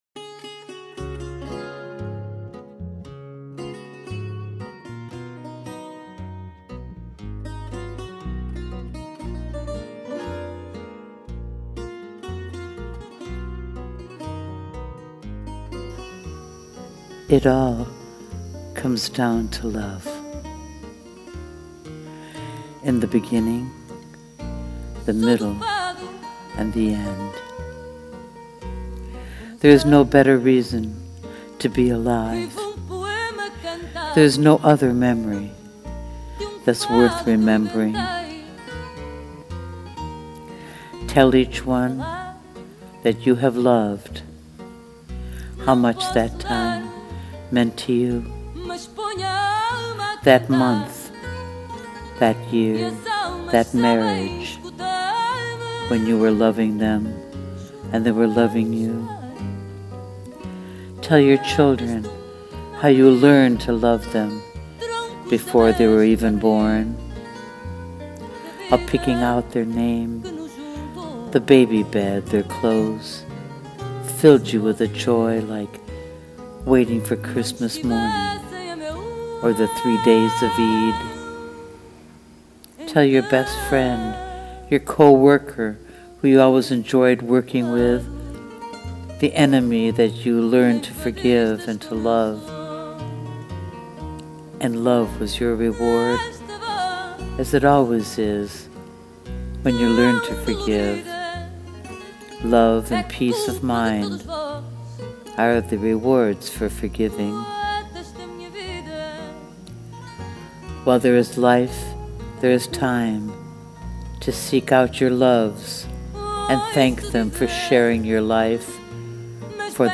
Como es usual, tu voz gusta, arrulla y deleita, siendo el mejor apoyo para realzar el contenido del poema.
And as ever, the perfect voice and music.